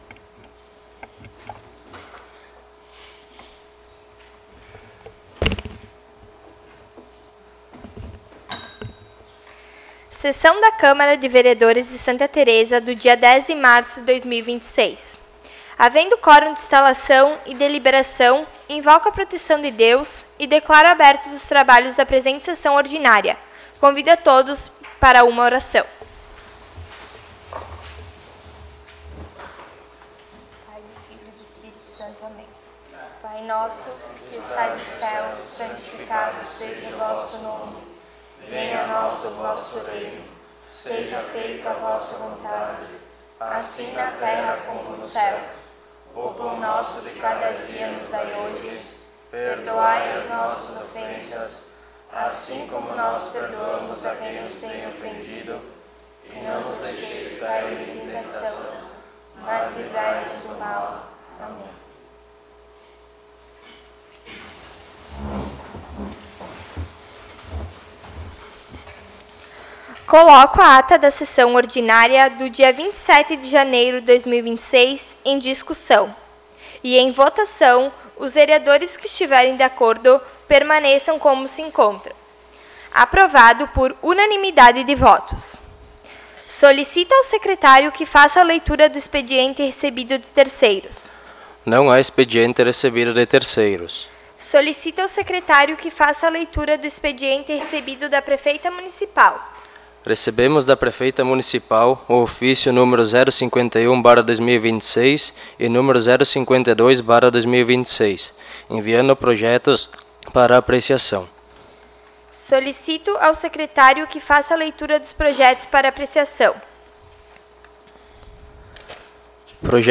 03° Sessão Ordinária de 2026